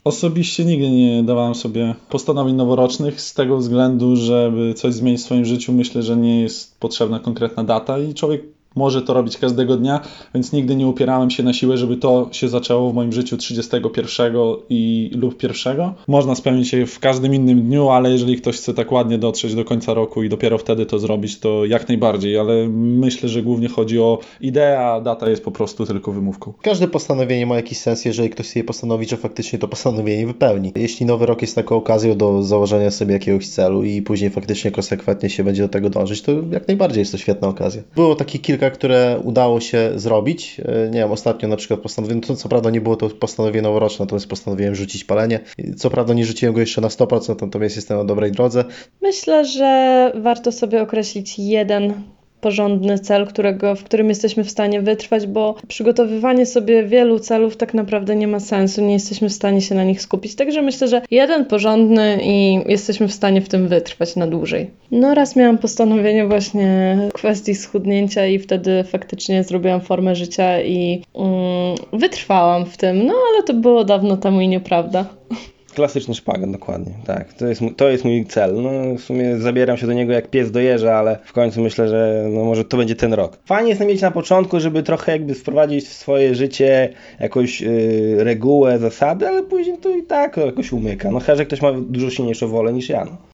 postanowienia-noworoczne-sonda.mp3